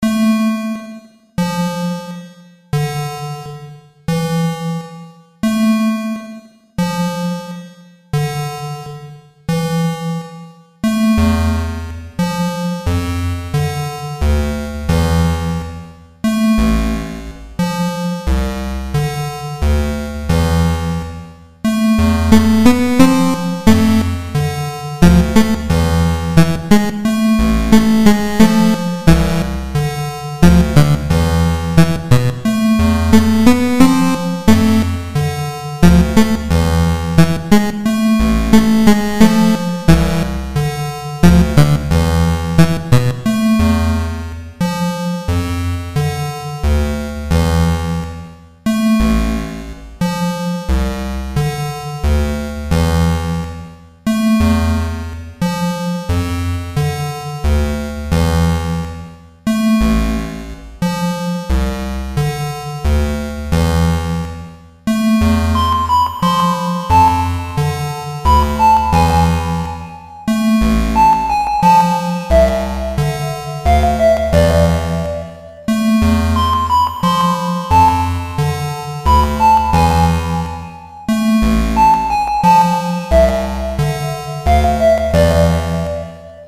So if you like music that sounds like it was made for a NES and don’t mind abrupt endings, you can check out some of the stuff I’ve been working on: